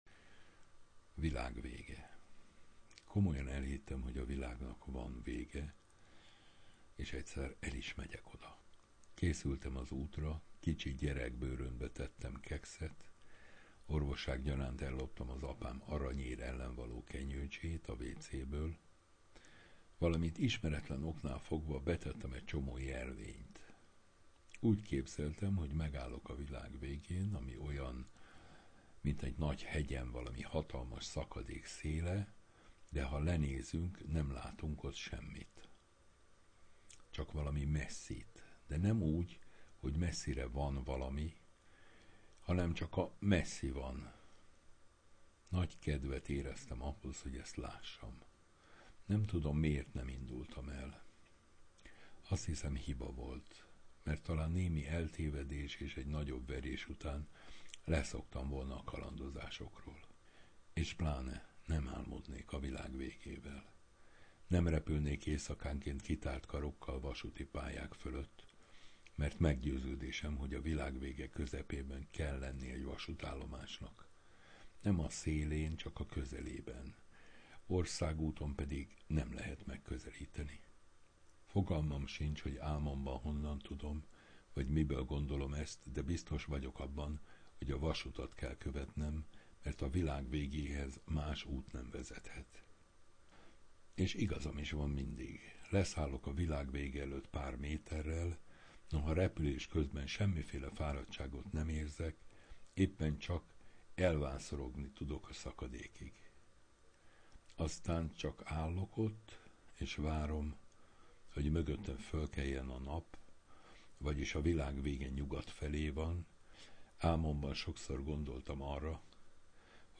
(Alább: a szerző felolvasásásban)